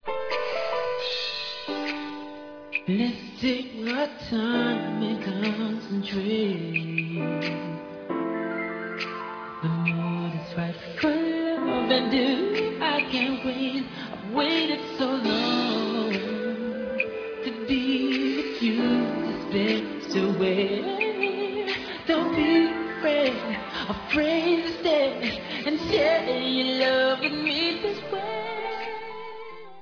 background vocals and keyboards